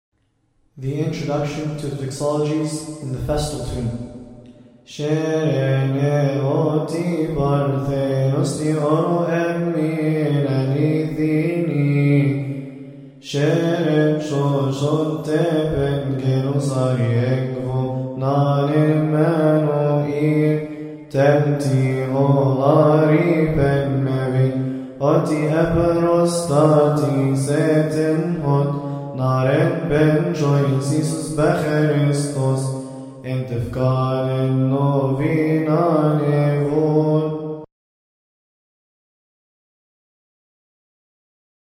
All hymns must be chanted according to the Higher Institute of Coptic Studies.
IntrotoDoxology-Joyful.mp3